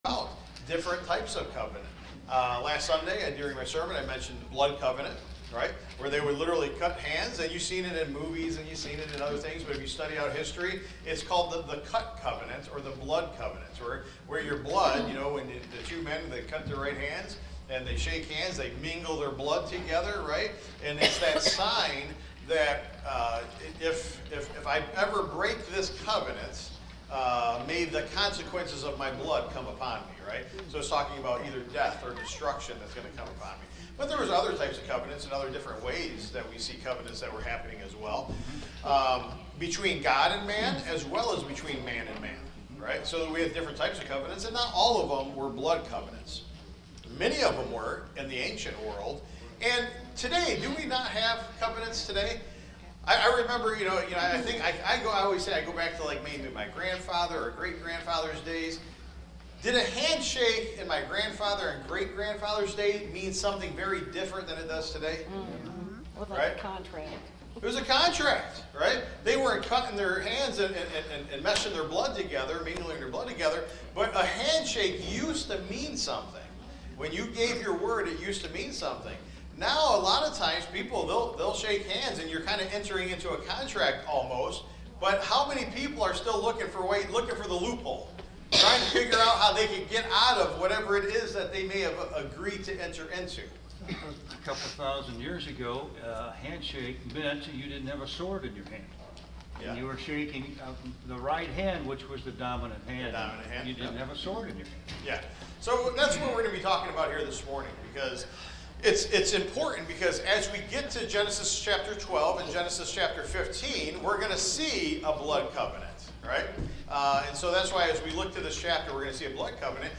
Taught live Sunday, June 8, 2025